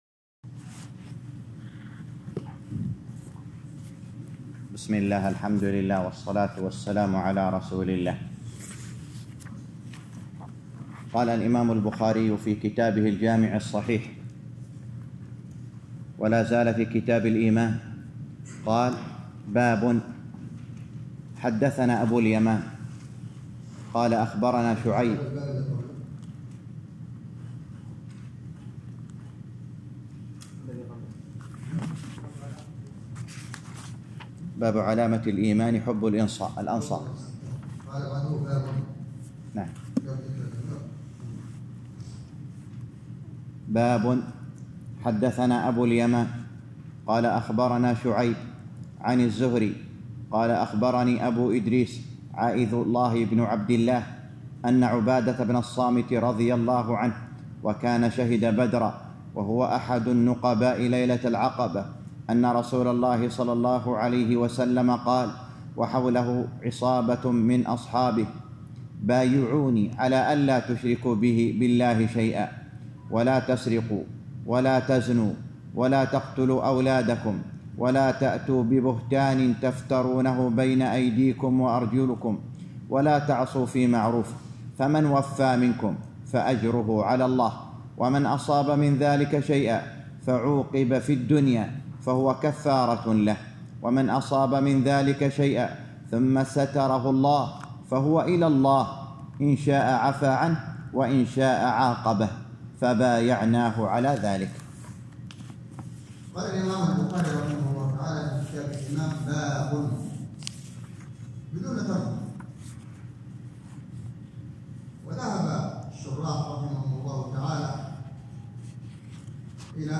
الدرس السادس - شرح صحيح البخاري كتاب الإيمان _ 6